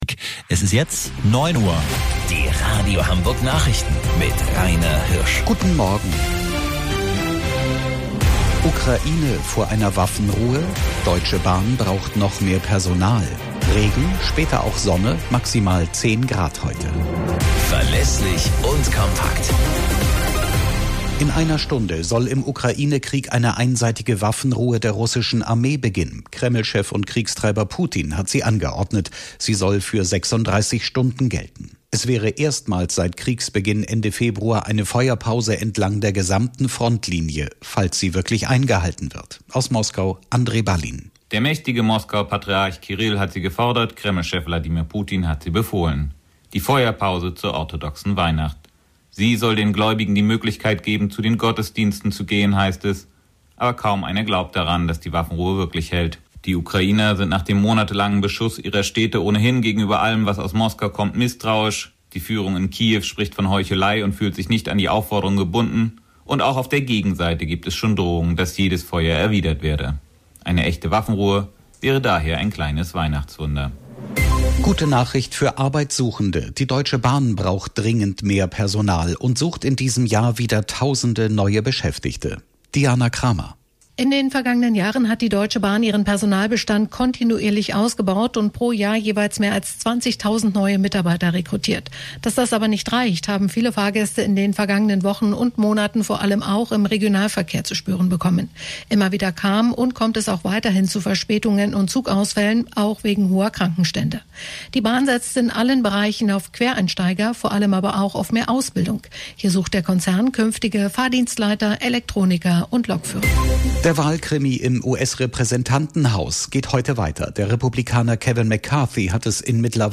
Radio Hamburg Nachrichten vom 17.06.2022 um 21 Uhr - 17.06.2022